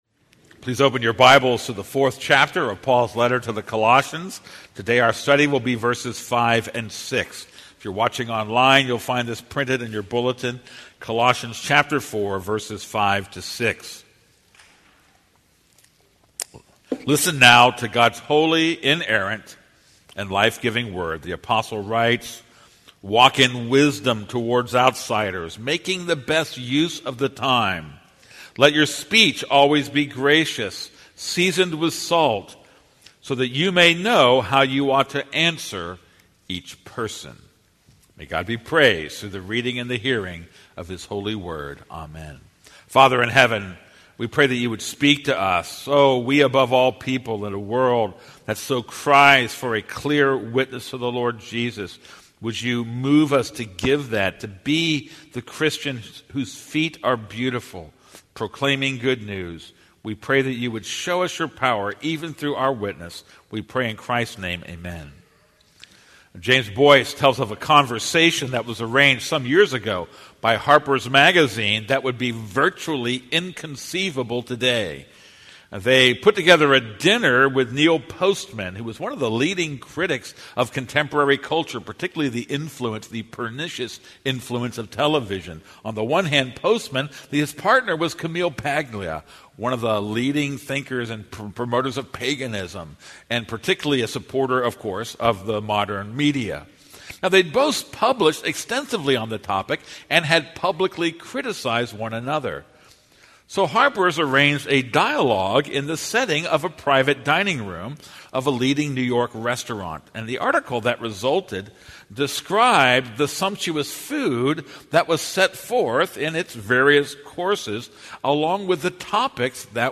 This is a sermon on Colossians 4:5-6.